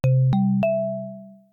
notification.wav